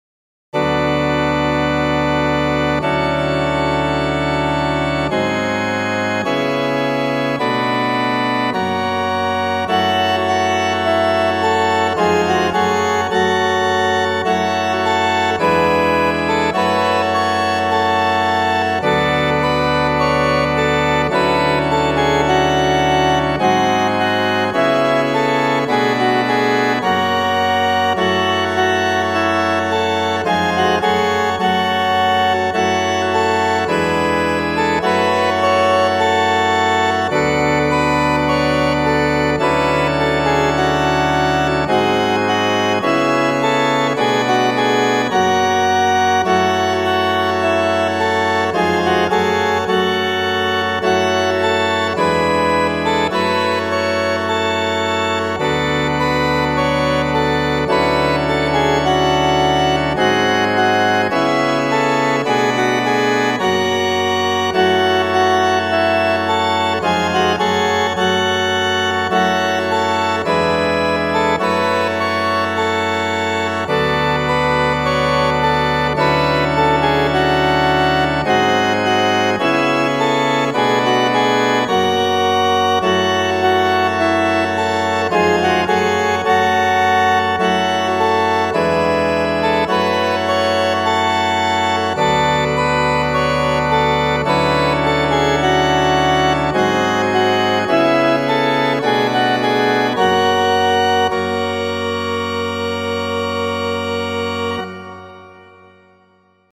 This is another hymn for Advent.
More fake BIAB organ: